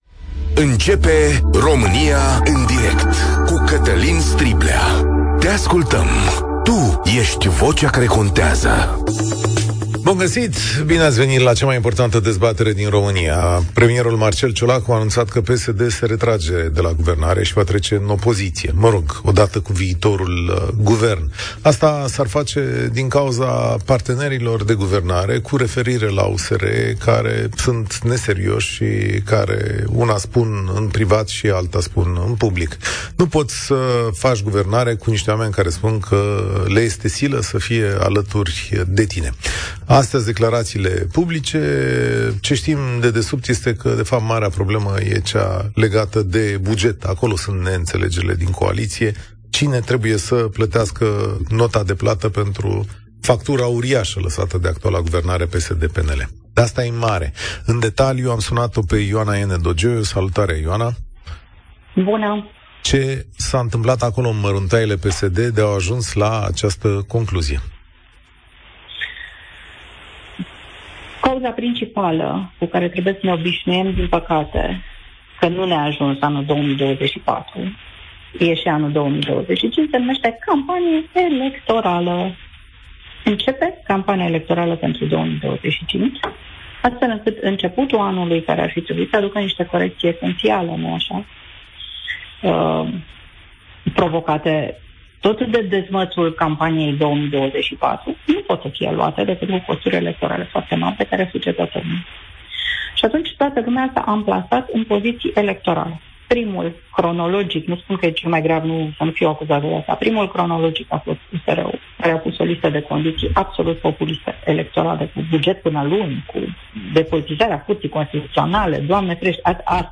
România se adună pe frecvenţele Europa FM pentru cea mai ascultată emisiune din ţară în care românii se fac auziţi.